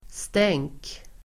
Uttal: [steng:k]